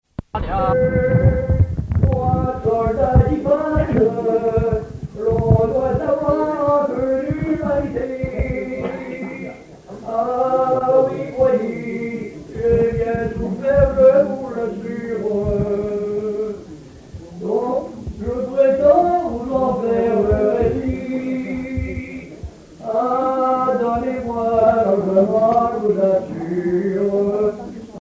enregistré dans le repas des anciens
Genre strophique
Pièce musicale inédite